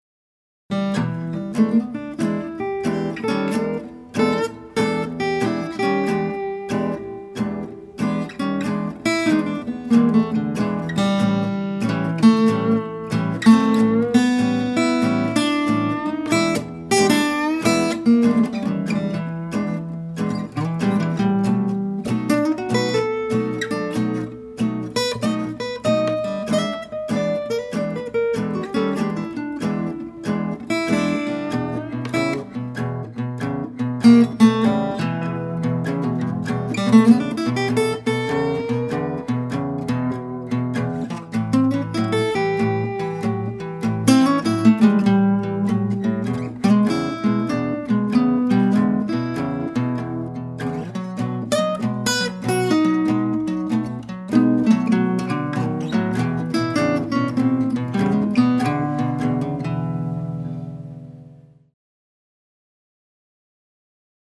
Need Bass & Drums for a Short Acoustic Blues
(There's a 1 bar lead-in added.)